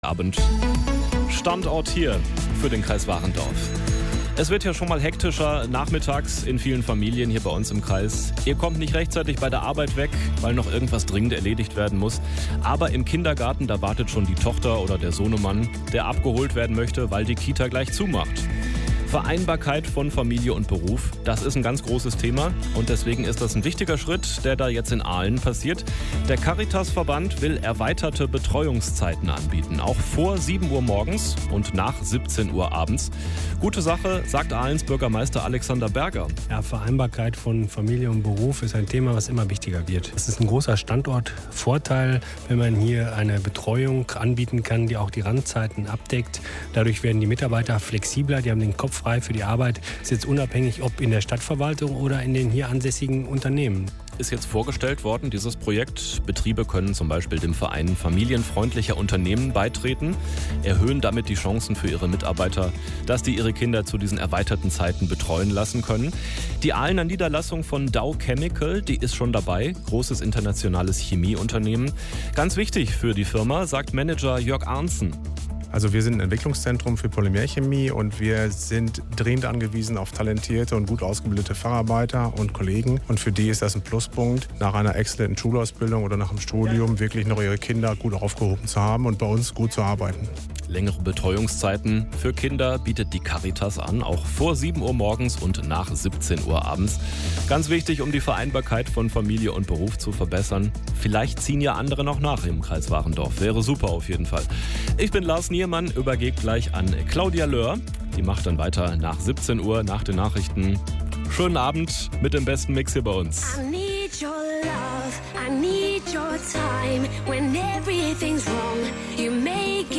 Donnerstags von 18 bis 19 Uhr werden Berichte, Interviews und Kollegengespräche gesendet. 2023 erfolgten 28 Ausstrahlungen. Schwerpunktthemen der gfw waren Veranstaltungshinweise, das Thema Gründung, der Award DIGITAL.PILOT 23 sowie Wirtschaftsnachrichten aus der Region.